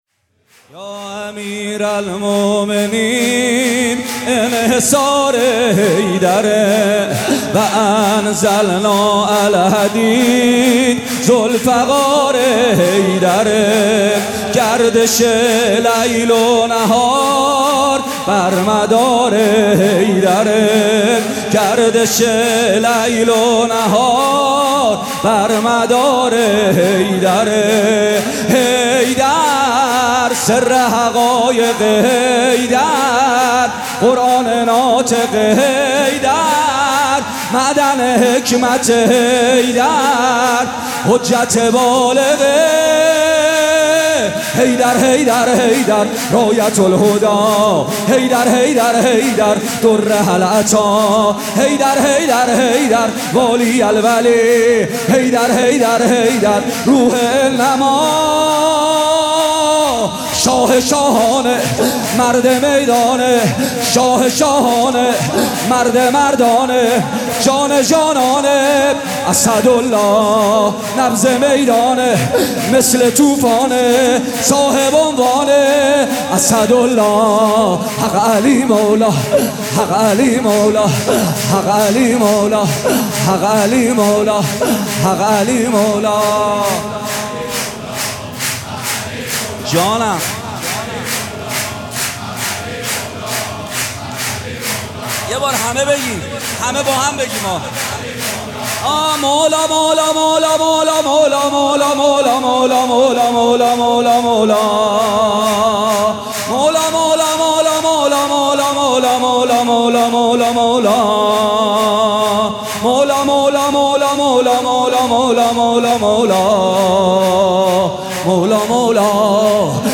مراسم جشن ولادت حضرت صاحب_الزمان (عج)
مدیحه سرایی :کربلایی محمدحسین پویانفر
سرود
جشن نیمه شعبان